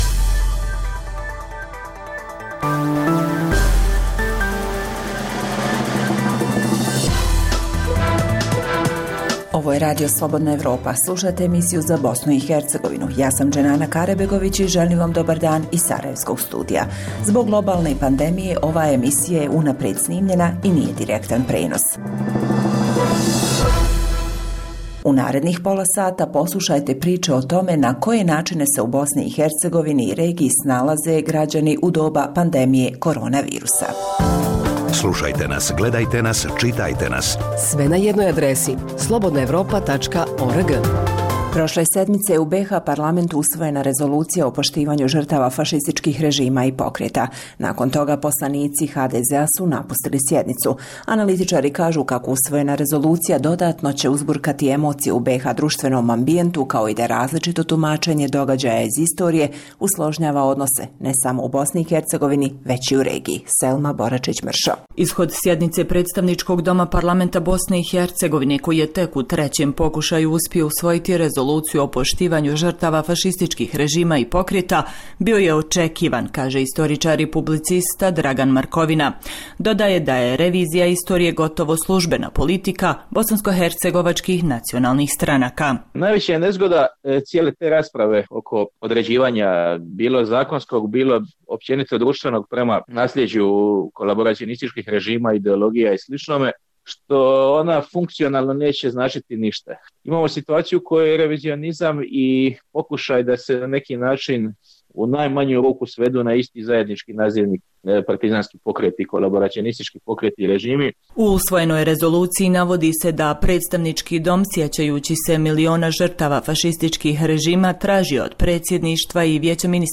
Zbog globalne pandemije ova emisija je unaprijed snimljena i nije direktan prenos. Poslušajte aktuelnosti i priloge o tome kako se građani Bosne i Hercegovine i regije snalaze u vrijeme pandemije korona virusa.